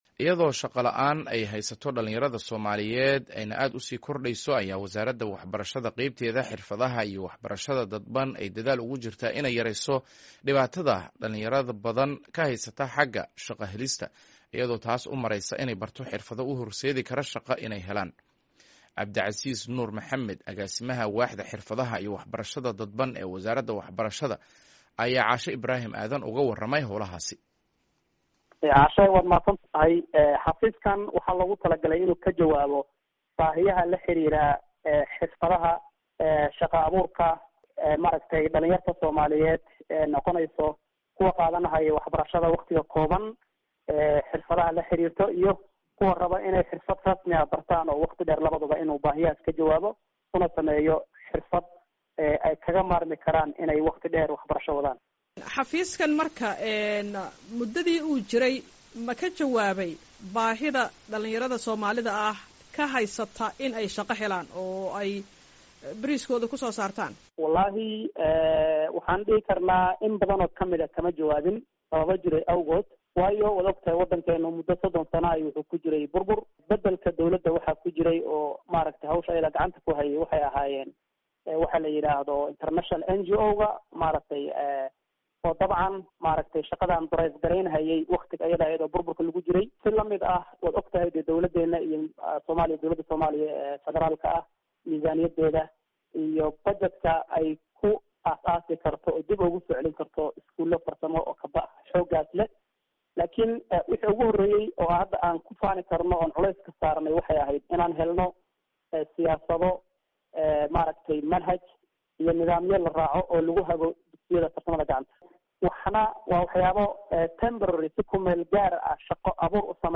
Wareysi: Dadaallada shaqo abuurka dhallinyarada ee Wasaaradda Waxbarashada